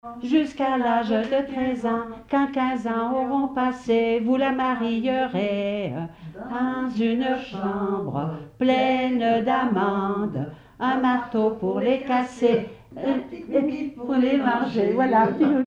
Saint-Julien-en-Genevois
enfantine : berceuse
Pièce musicale inédite